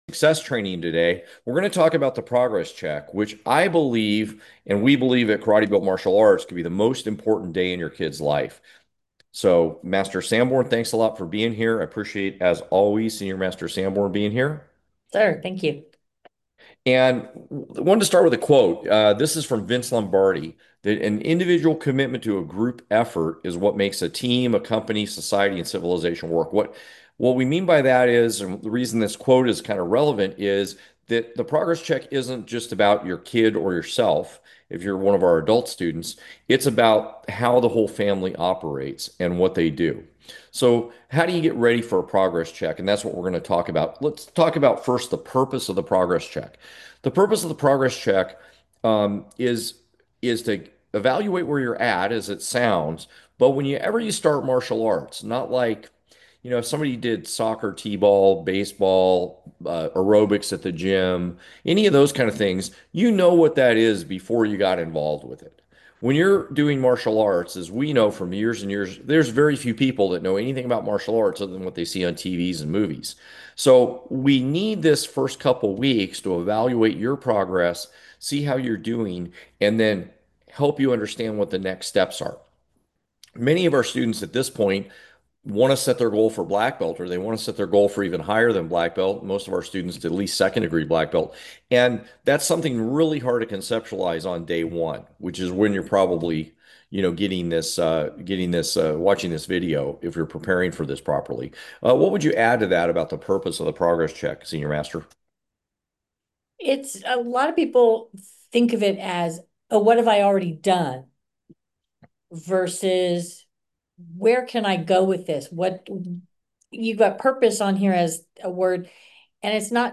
In today’s Success Training conversation